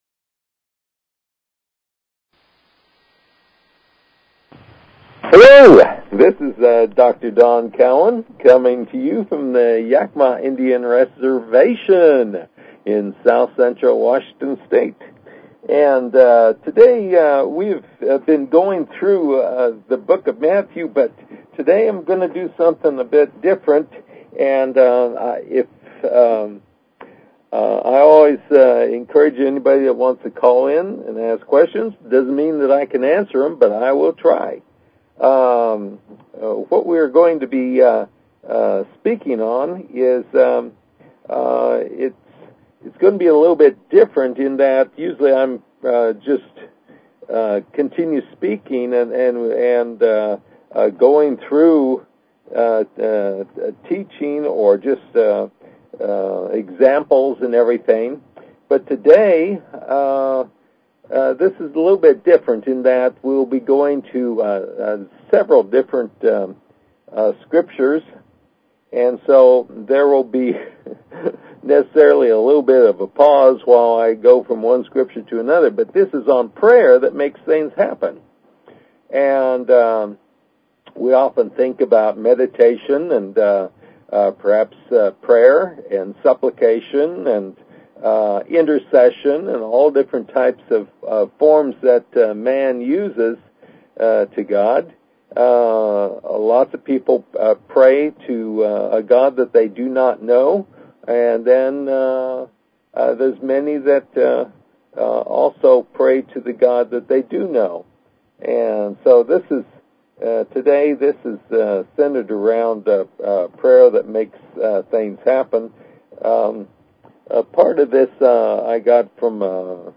Talk Show Episode, Audio Podcast, New_Redeaming_Spirituality and Courtesy of BBS Radio on , show guests , about , categorized as